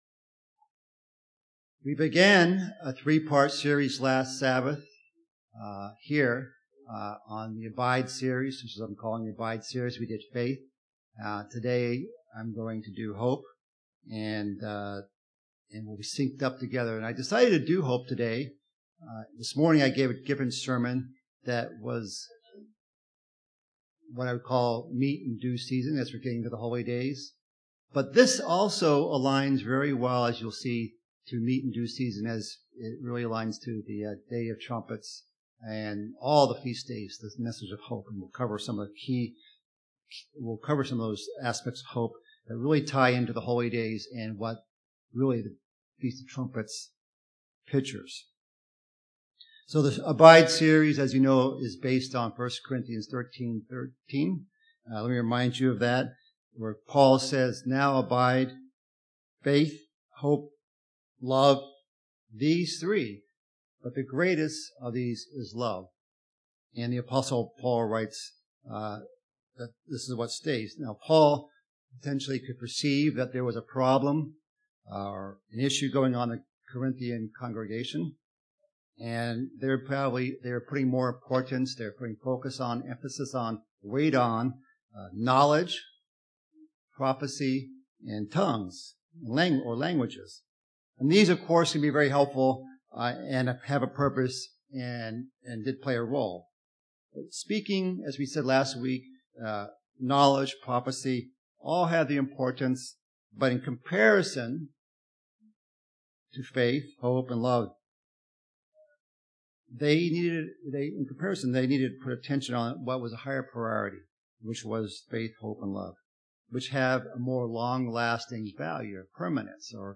The second sermon in the Abide Series, given in the Munster, Indiana congregation.